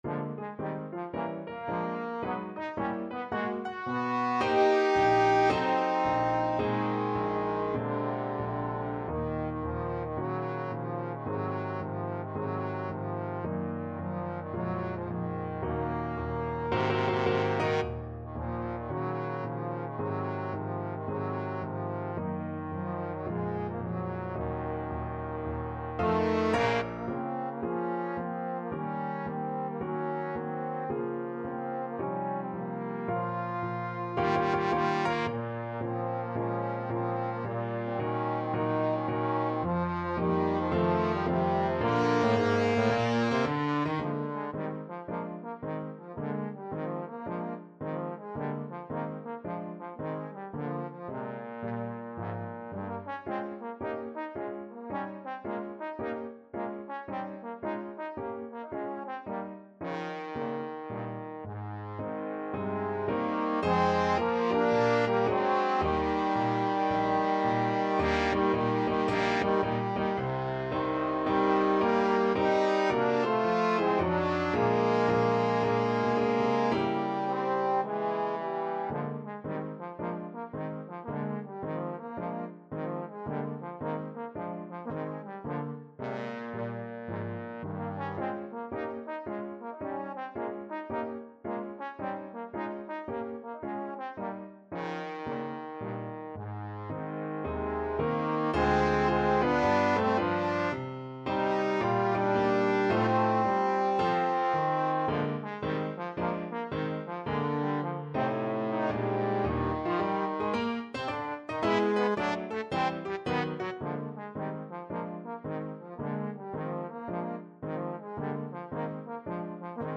Moderato =110 swung
Trombone Duet  (View more Intermediate Trombone Duet Music)
Classical (View more Classical Trombone Duet Music)